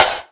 Index of /90_sSampleCDs/EdgeSounds - Drum Mashines VOL-1/SIMMONSDRUMS